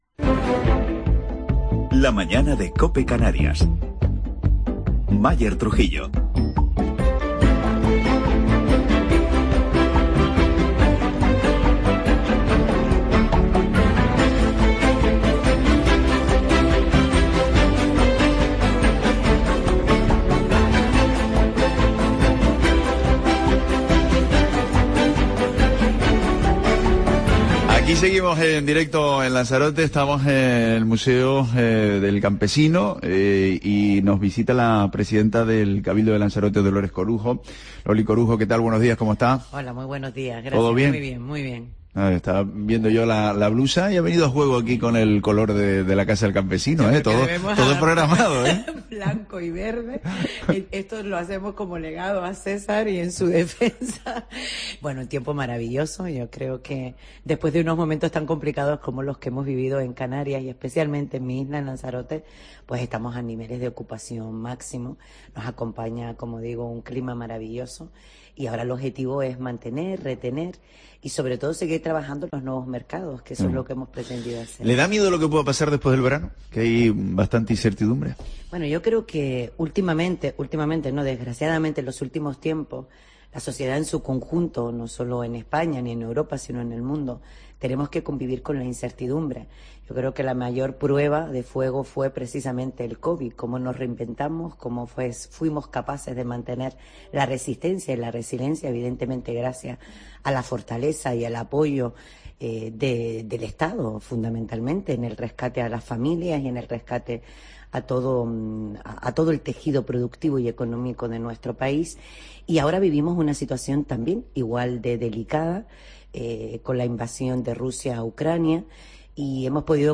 Entrevista a Dolores Corujo desde la Casa-Museo del Campesino en Lanzarote